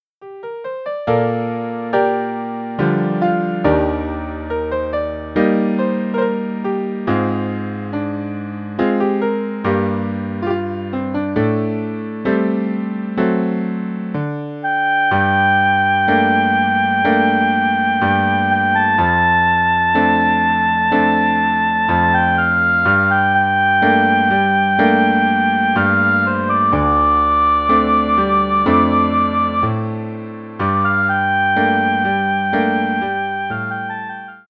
traditional Bashkir folk song